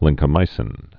(lĭngkə-mīsĭn)